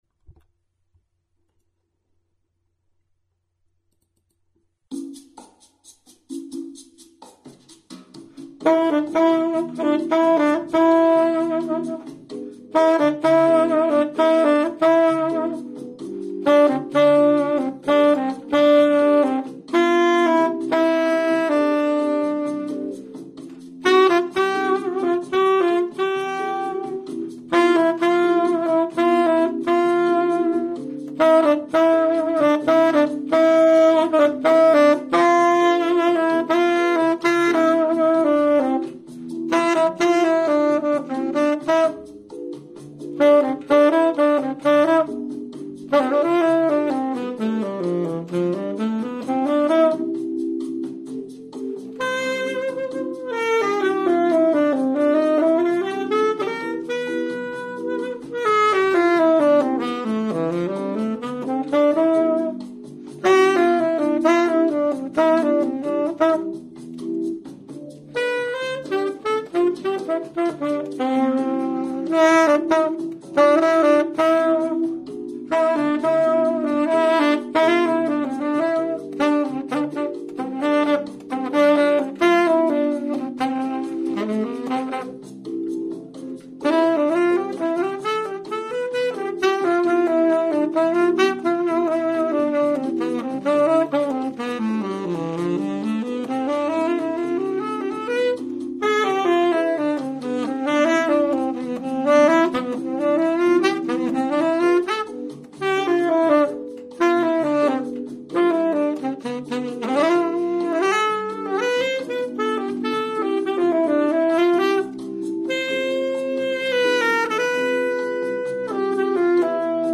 TENOR SAX AUDIO RECORDINGS !
All the tenor tunes recorded in Toulouse (France)